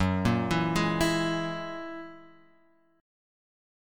F#9 chord {2 1 2 1 x 2} chord